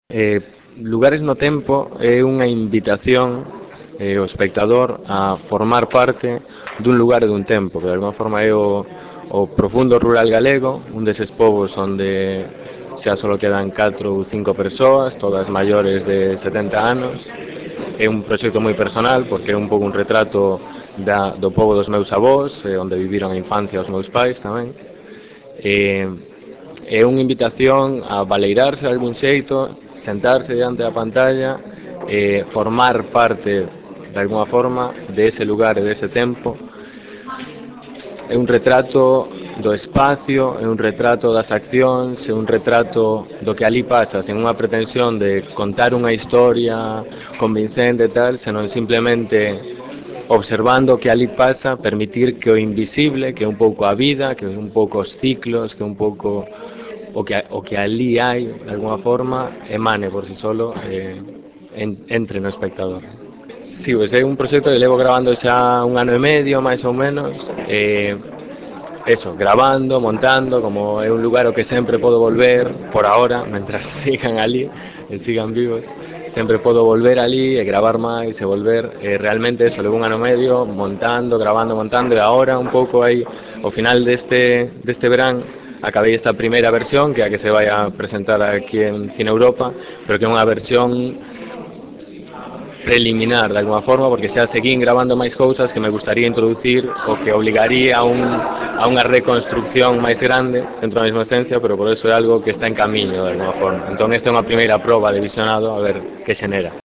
Entrevista en audio